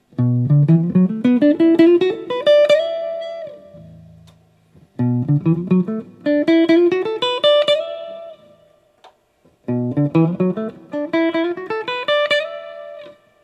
I did two things while recording these snippets: a chord progression and a quick pentatonic lick.
1984 X-500 Lick
With the guitars plugged in using equal amp settings, you can hear how the 1984 X-500 without the sound post is much boomier when the neck pickup is involved.